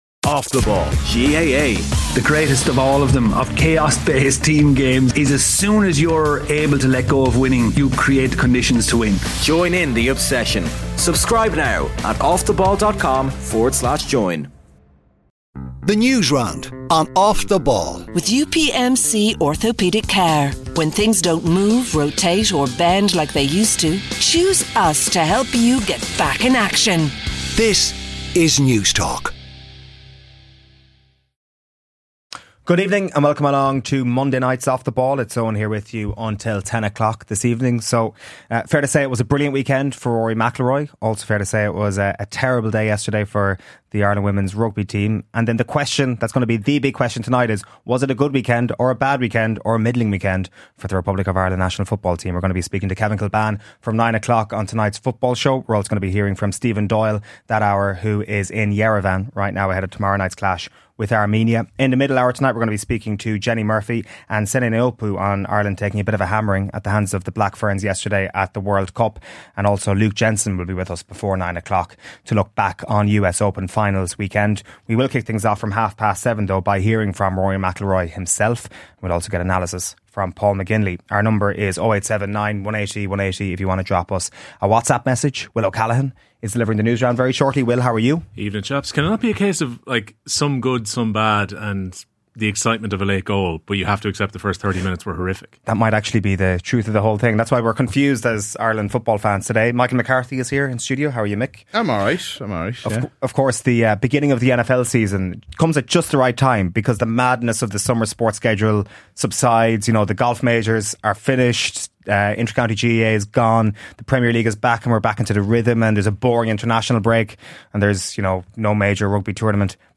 All-Ireland Final predictions, tactics, match-ups and 3 Darts rule at The Football Pod Live in Croke Park | Part Two - 26.07.2025